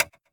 metronomeRight.ogg